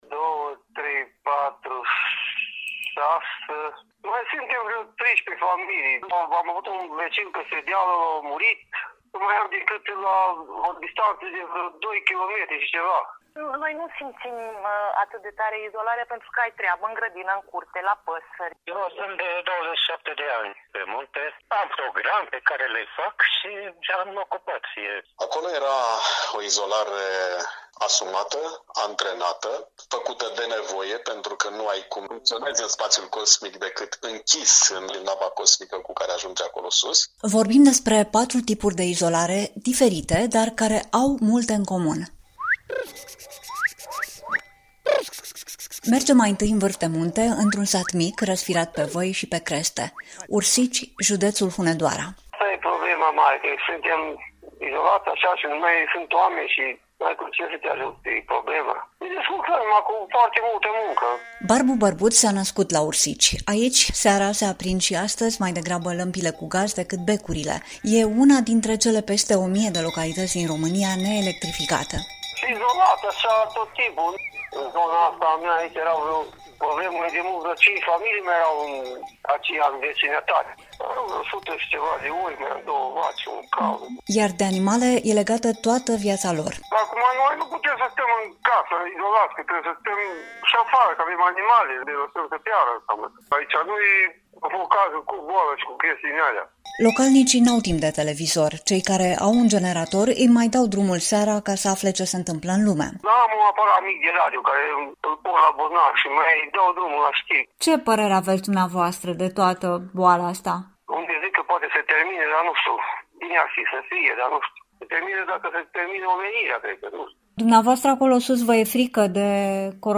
Lumea Europa FM: Povești despre izolare, ca mod de viață | REPORTAJ : Europa FM